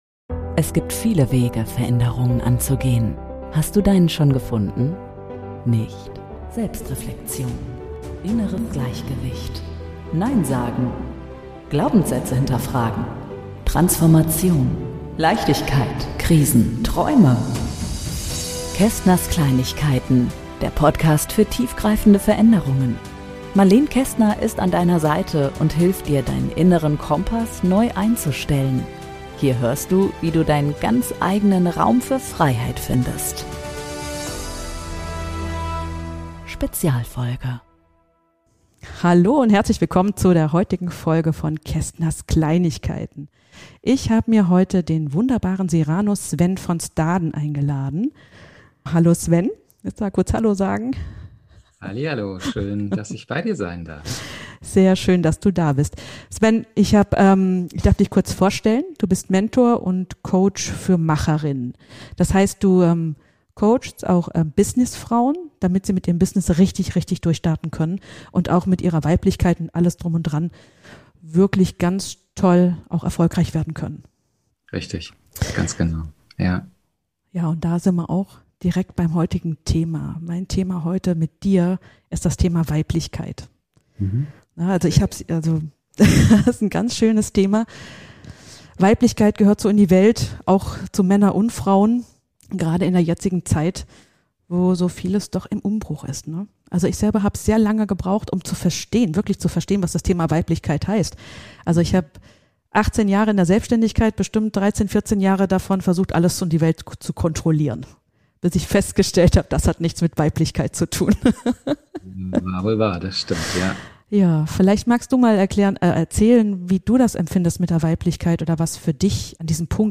#005 Weiblichkeit in der heutigen Zeit - ein Interview